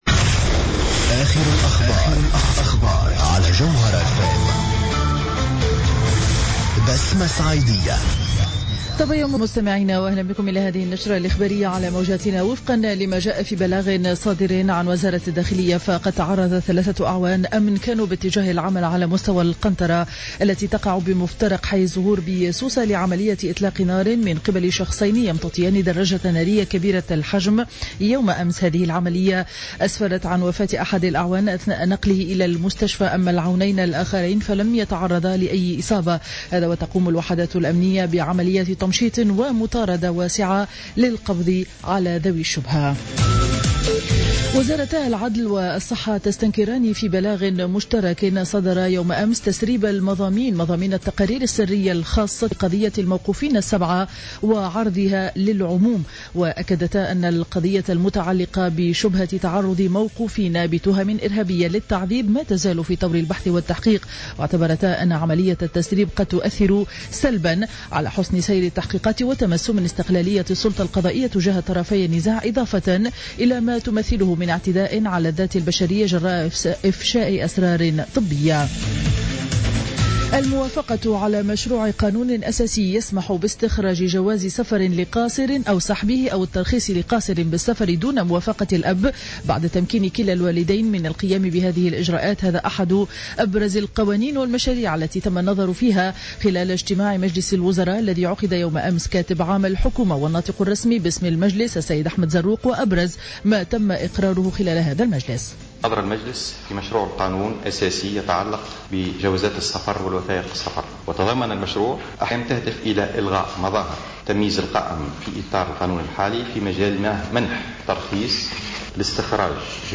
نشرة أخبار السابعة صباحا ليوم الخميس 20 أوت 2015